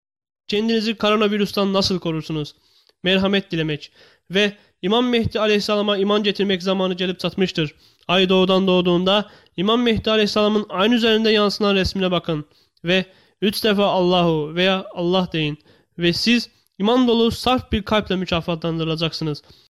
土耳其语样音试听下载